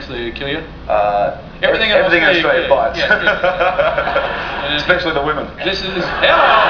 David Letterman Interview (audio) Celluloid Interview